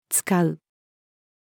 遣う-female.mp3